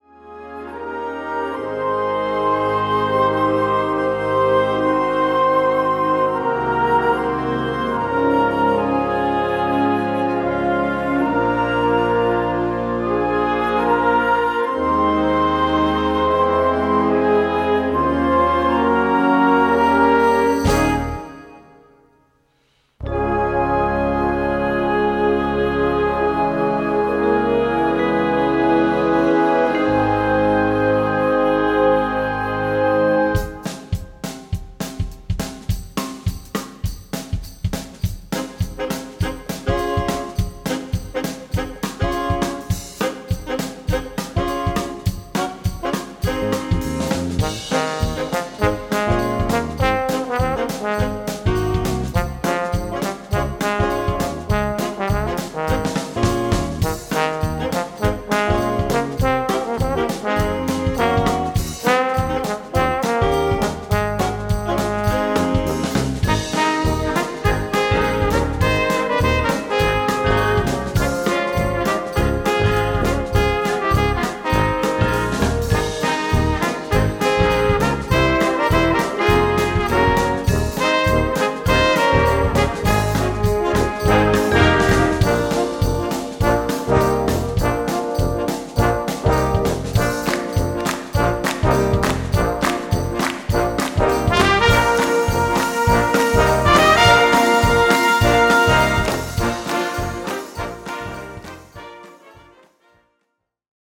Gattung: Gospel-Stil
Besetzung: Blasorchester
Two-Beat-Gospel-Stil
lebendige und mitreißende Version